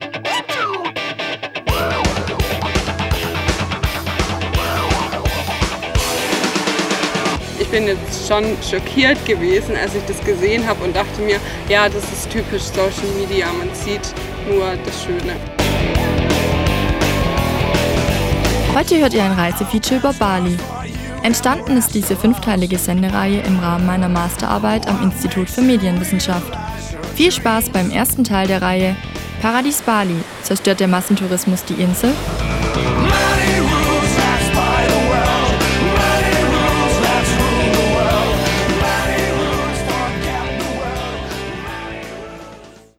Teil 1 eines Reiseberichts.